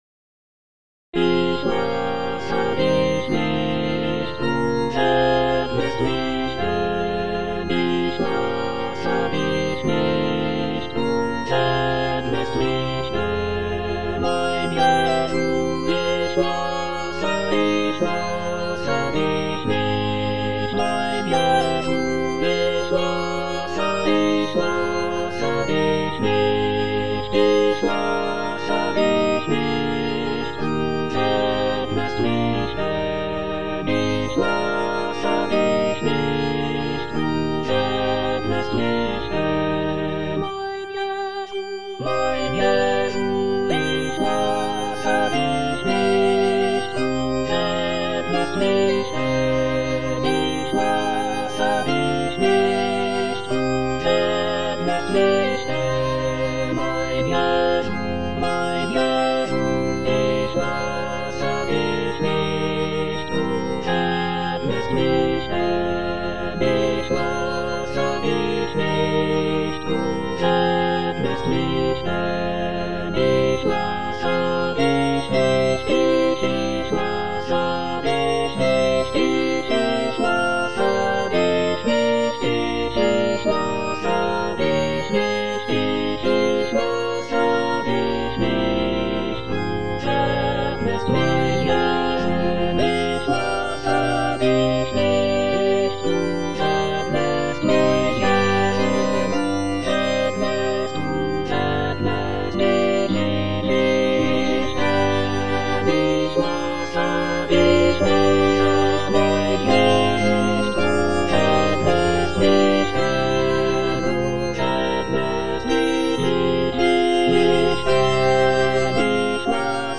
Choralplayer playing Cantata
All voices